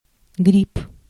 Ääntäminen
US : IPA : [ˈmʌʃ.rʊm] GenAm: IPA : /ˈmʌʃˌruːm/